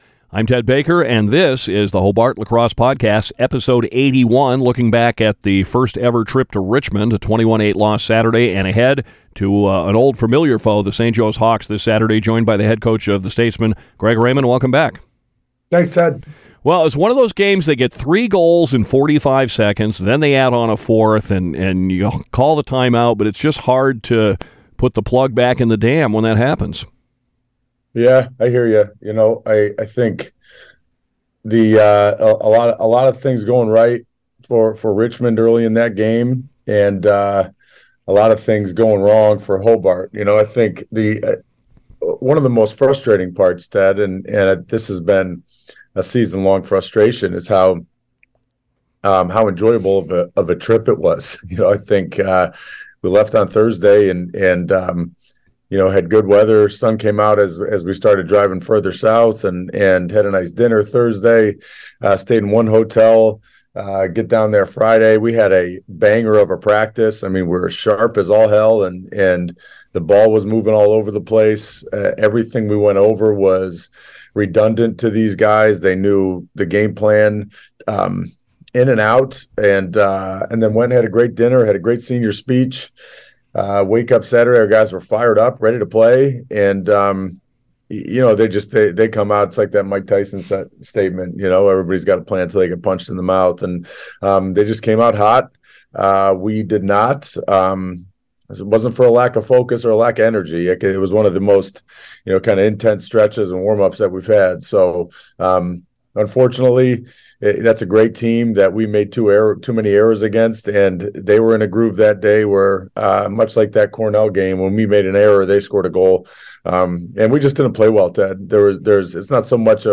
The Hobart Lacrosse Podcast is recorded before each game during the season and monthly during the offseason.